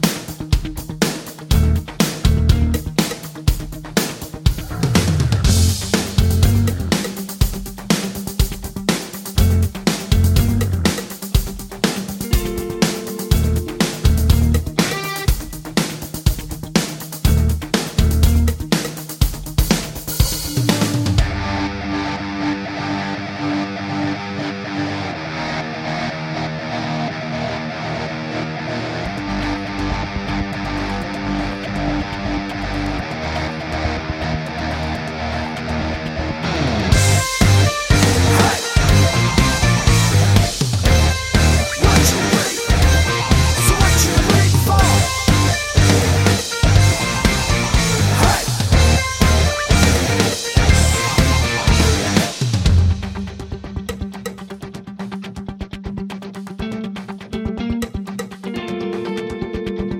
Clean Version Rock 3:23 Buy £1.50